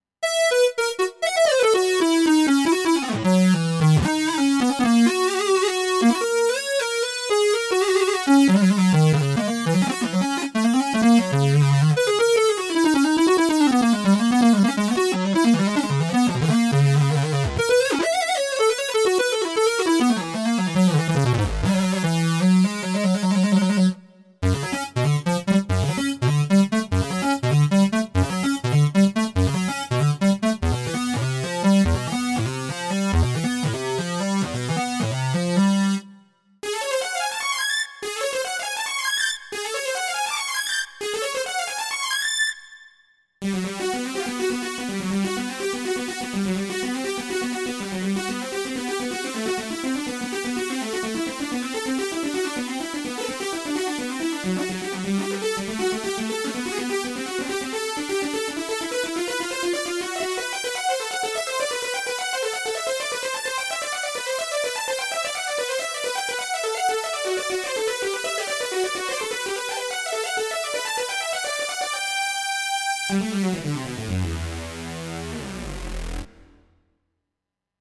Metropolis_-leads-instrumental-part.mp3